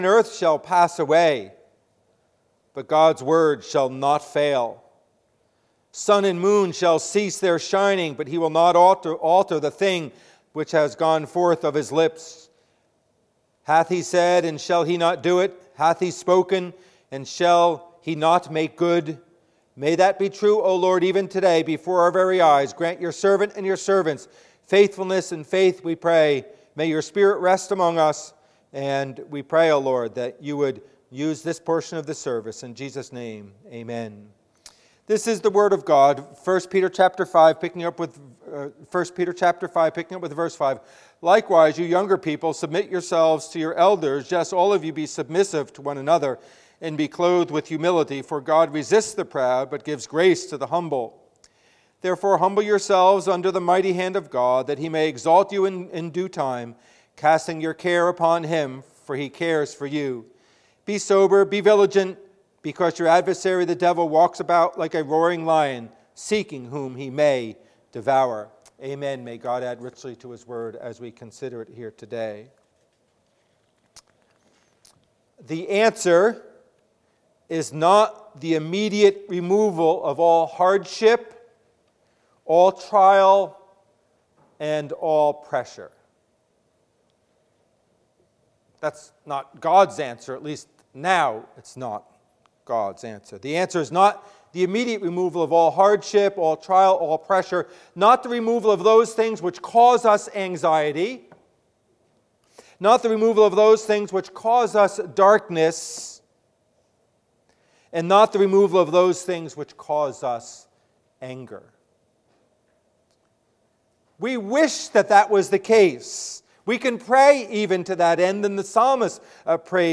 Passage: 1 Peter 5:5-8 Service Type: Worship Service « Having a Deep Sense of One’s Littleness You Are an Emotional Being.